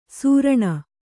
♪ sūraṇa